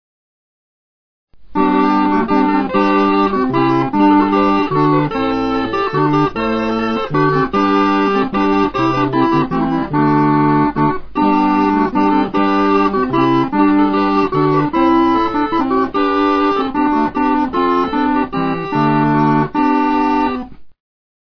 The picture shows a set of cornamusen (which are essentially straight crumhorns) consisting of a bass, two tenors, an alto and a soprano.
The harder wood gives this instrument a brighter tone.
They are multitracked recordings the first two arranged for two sopranos, an alto and a bass, the third for an alto two tenors and a bass.